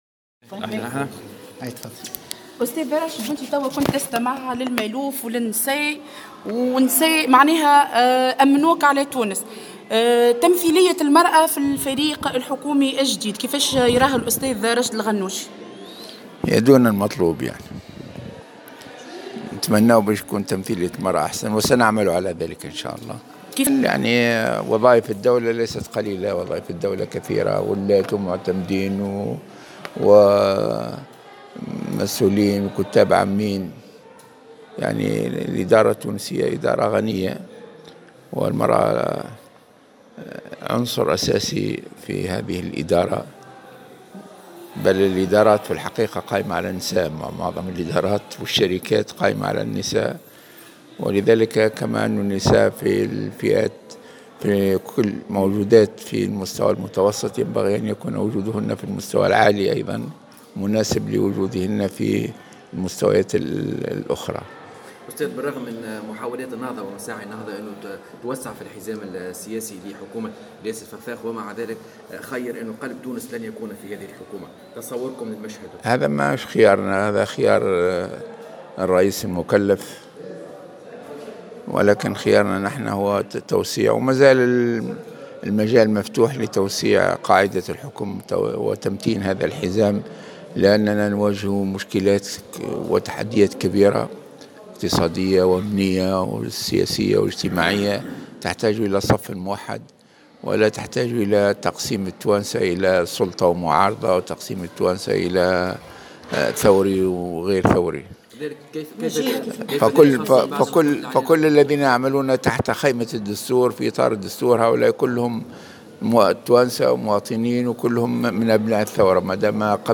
أكد رئيس حركة النهضة راشد الغنوشي اليوم السبت في تصريح لموفد "الجوهرة اف أم" انه لا أحد يستطيع ارساء حكومة دون النهضة.